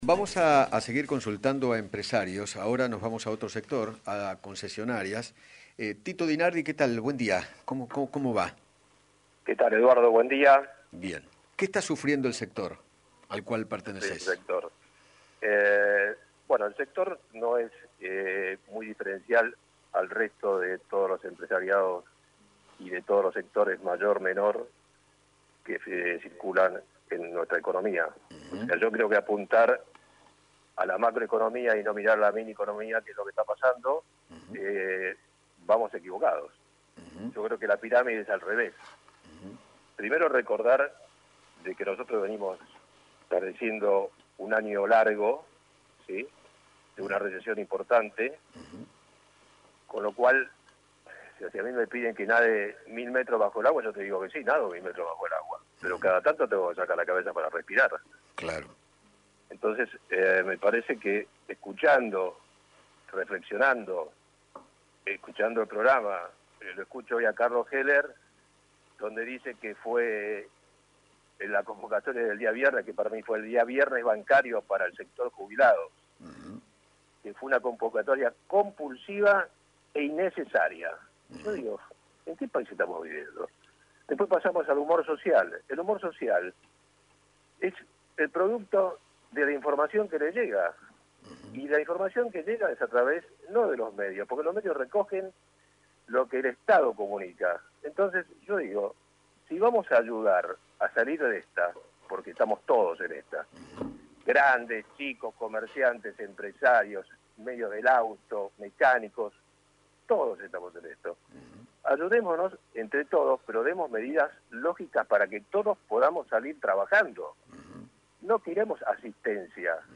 Silvana Giudici, ex Presidenta de la Comisión de Libertad de Expresión de la Cámara de Diputados, dialogó con Eduardo Feinmann sobre la polémica del ciberpatrullaje.